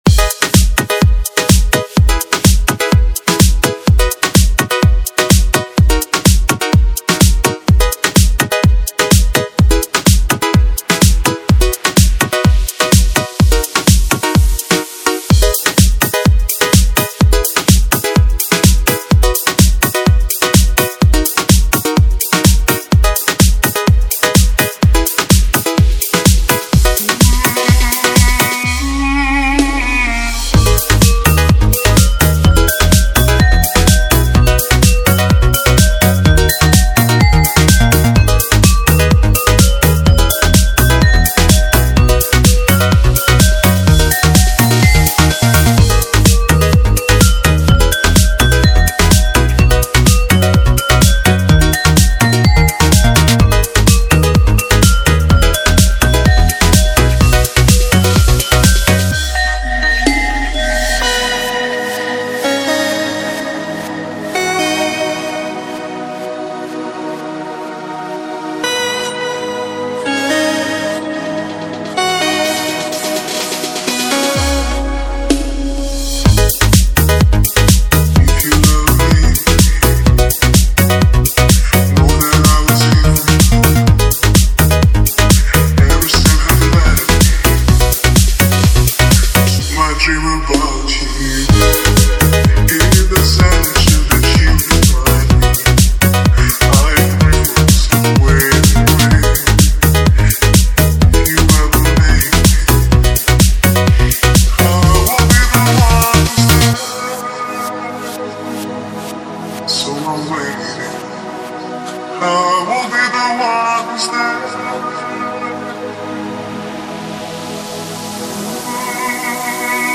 2024-06-07 19:00:27 Gênero: Internacional Views